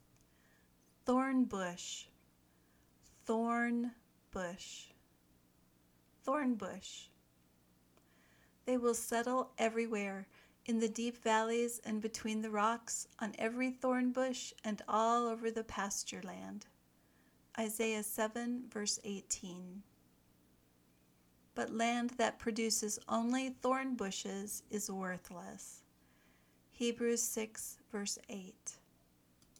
ˈθɔrn  bʊʃ  (noun)